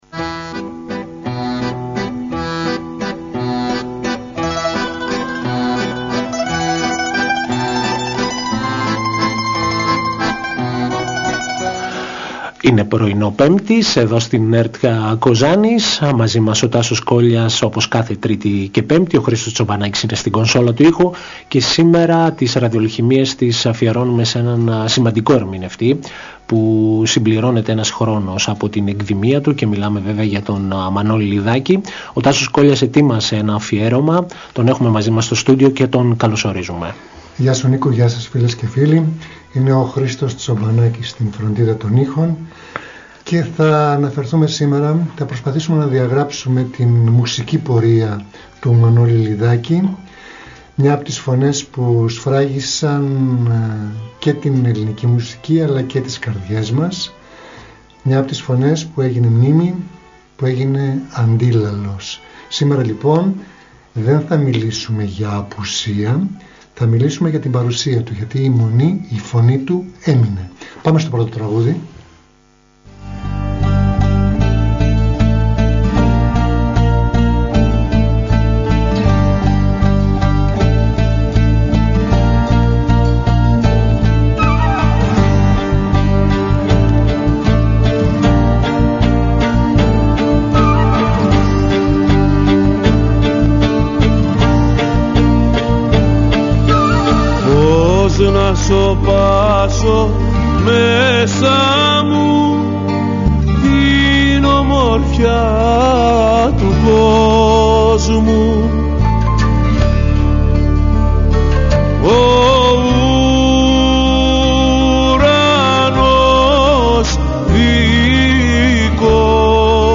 Μια εκπομπή μουσικής και λόγου διανθισμένη με επιλογές από την ελληνική δισκογραφία.